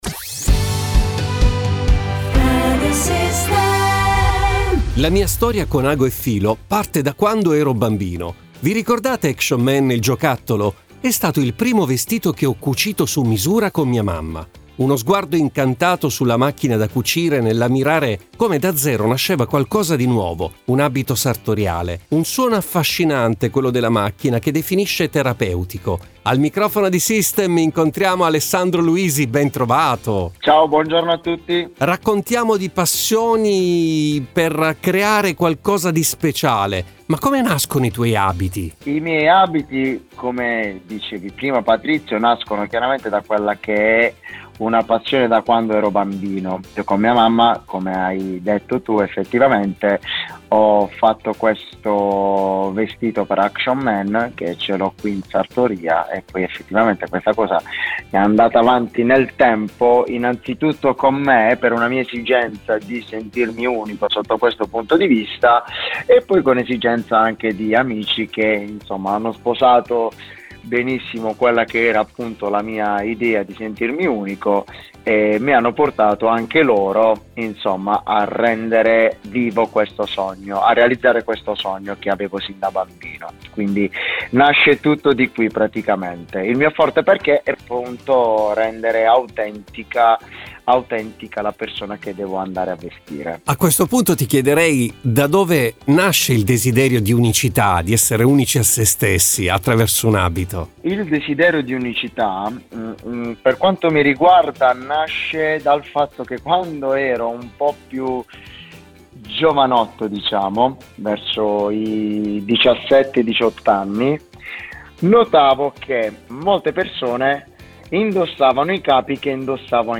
Un’altra storia da conoscere, ai microfoni di Radio System con l’intervista